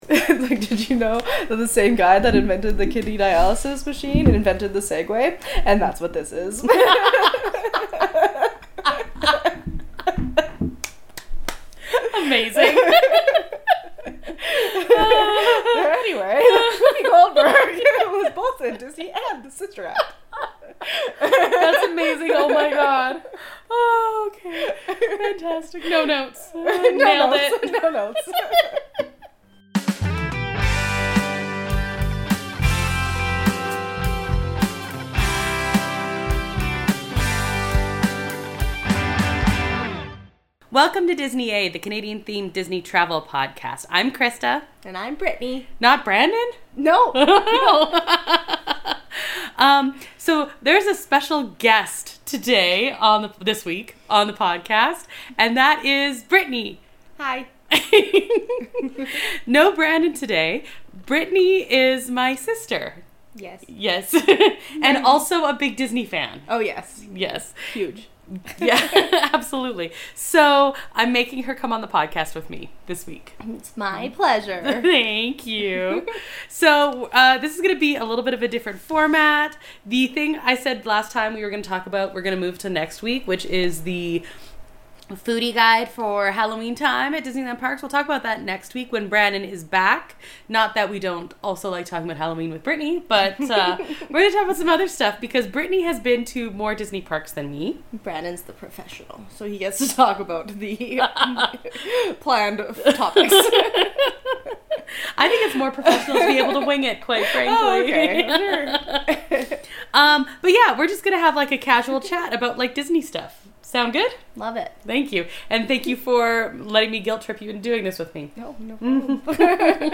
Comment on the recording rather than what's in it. It's a thorough and casual chat about our home park in another country, complete with memories and wishlists.